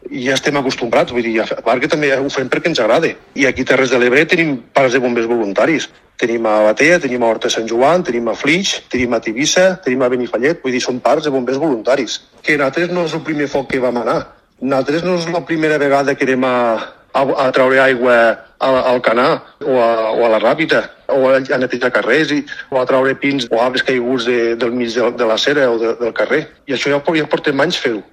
[ENTREVISTA] Els bombers voluntaris alcen la veu: «no és voluntariat, estem treballant com a bombers»